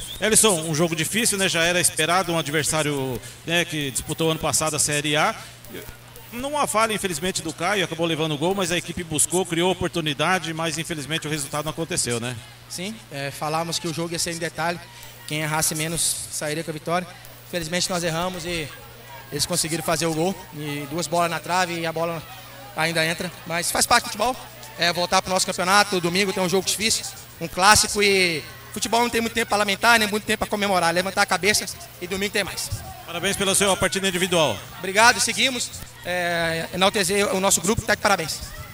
Jogador falou no final da partida a reportagem da Rádio EsporteMS